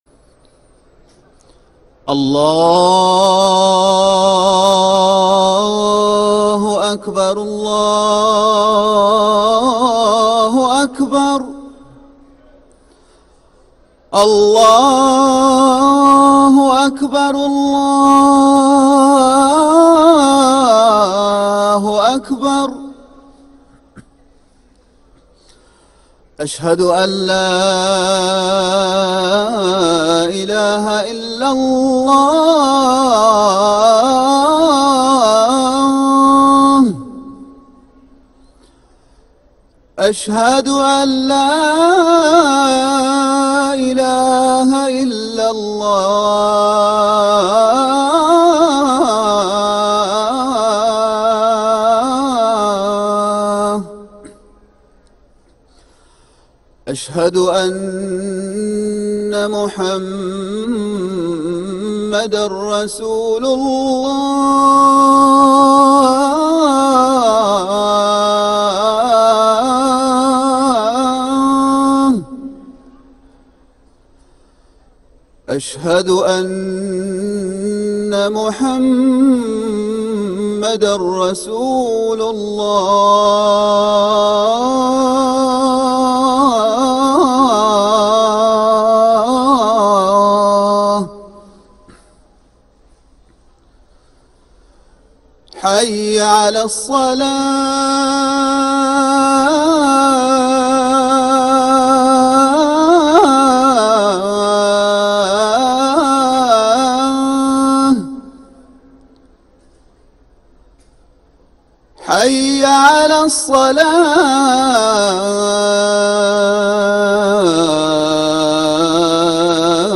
ركن الأذان